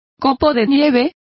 Complete with pronunciation of the translation of snowflake.